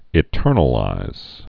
(ĭ-tûrnə-līz)